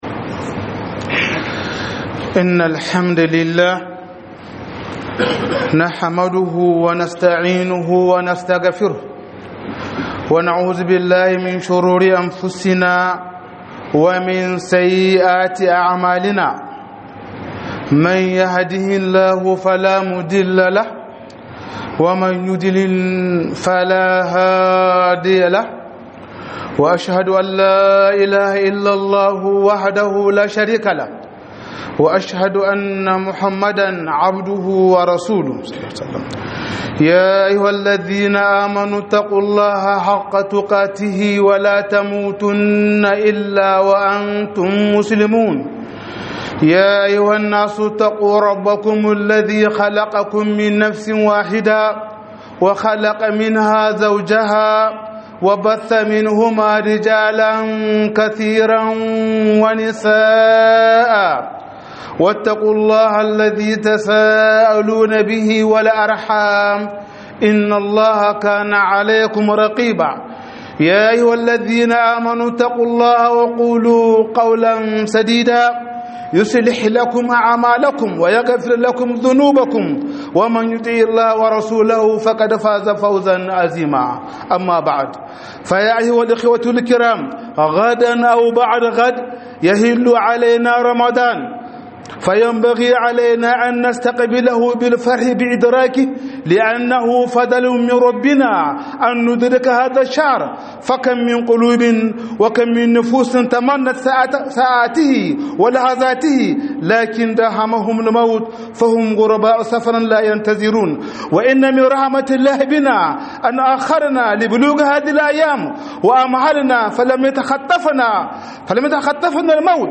002 Ramadan Ni'imar Allah ce ga bawa - HUDUBA